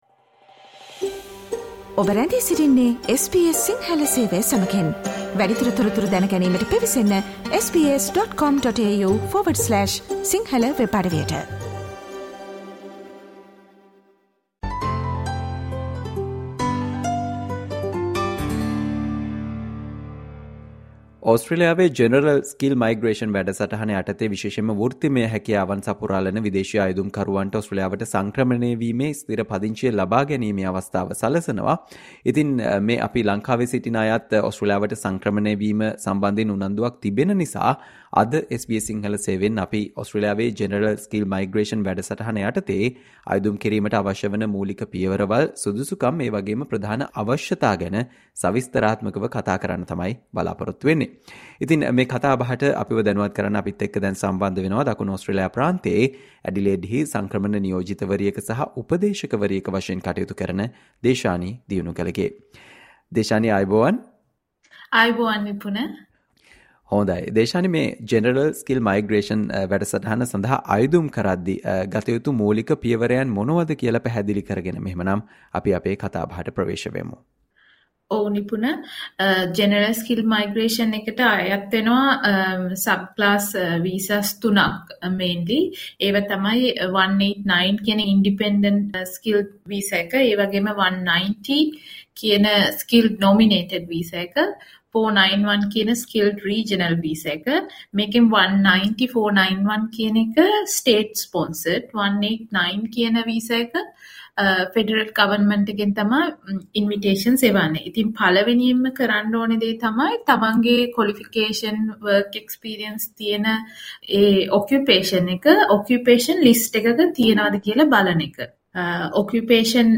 පුහුණු ශ්‍රමිකයින් වශයෙන් අයදුම් කොට ඕස්ට්‍රේලියාවේ ස්ථිර පදංචිය ලබාගත හැකි General Skilled Migration (GSM) වැඩසටහන ට අයදුම් කරන ආකාරය සහ අවශ්‍ය මූලික සුදුසුකම් ගැන SBS සිංහල සේවය සිදු කල සාකච්චාවට සවන් දෙන්න